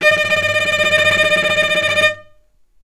healing-soundscapes/Sound Banks/HSS_OP_Pack/Strings/cello/tremolo/vc_trm-D#5-mf.aif at 01ef1558cb71fd5ac0c09b723e26d76a8e1b755c
vc_trm-D#5-mf.aif